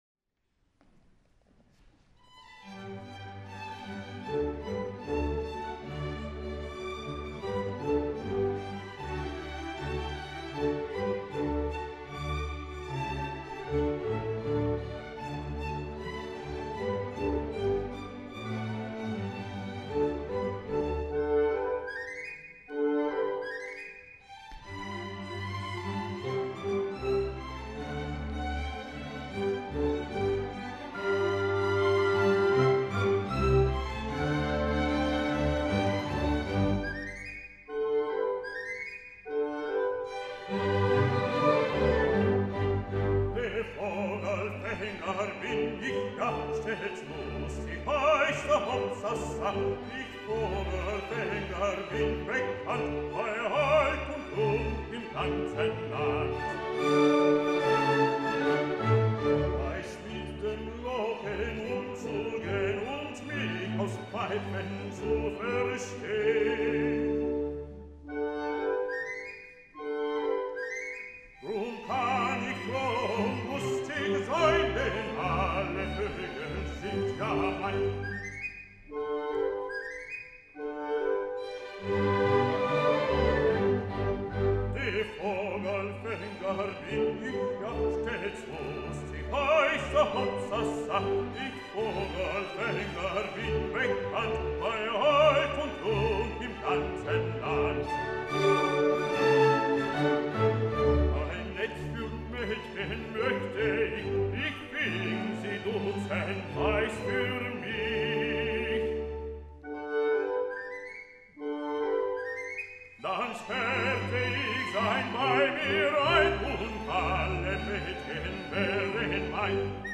Recordings of the Silicon Valley Symphony
Concert recordings
Sunday, March 4, 2012: St. Mark’s Episcopal Church
Baritone Soloist